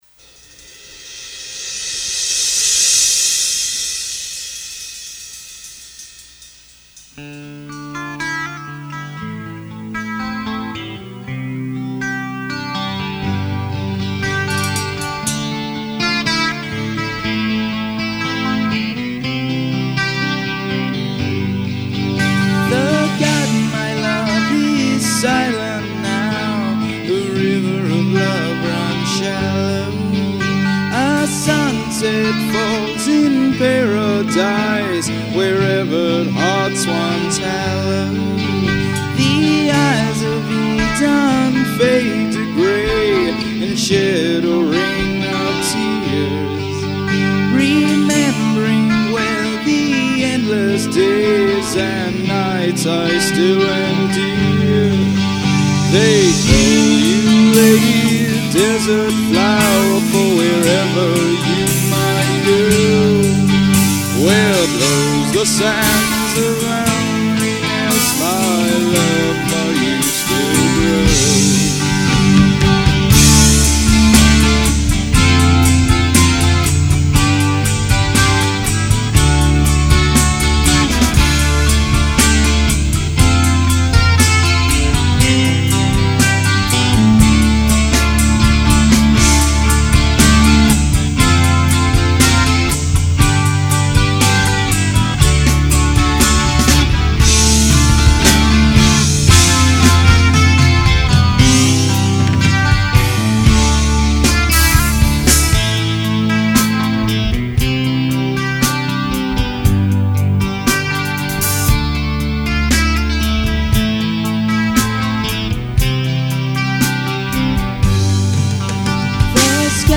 REMASTERED